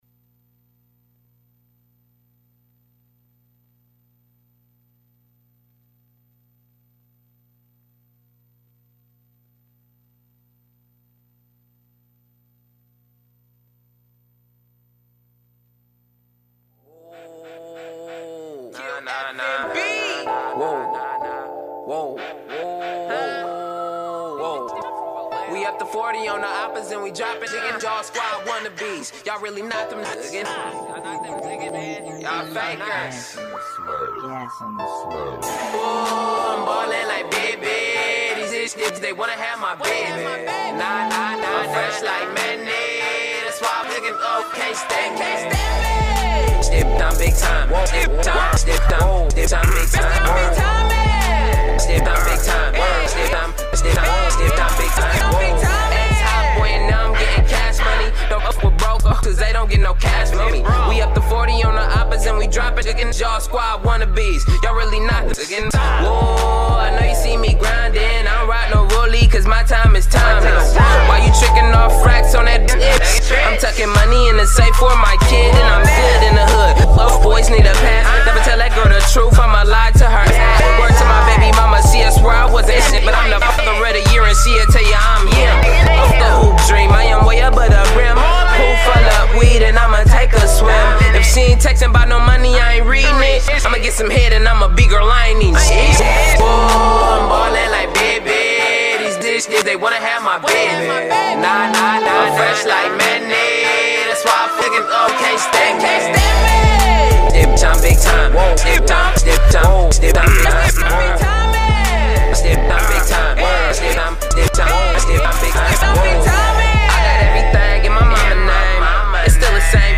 Youth Radio Raw is a weekly radio show produced by Bay Area youth, ages 14-18.